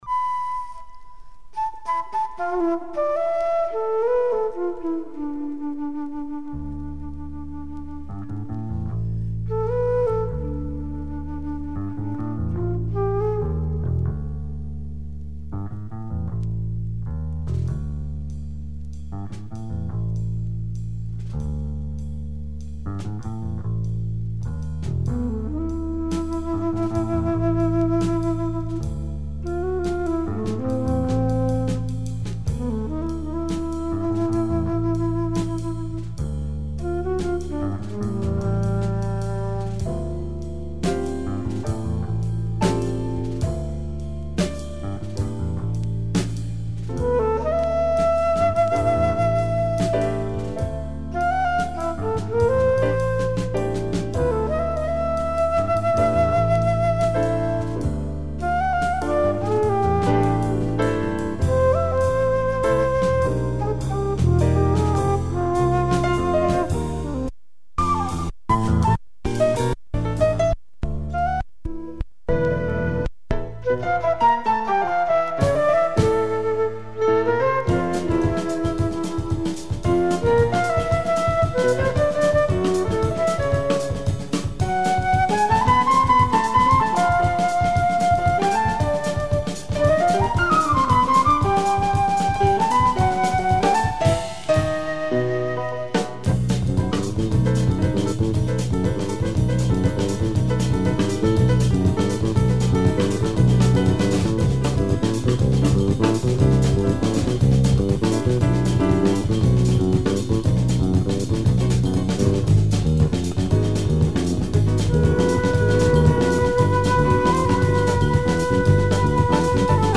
Record is slightly warped, listening is perfect.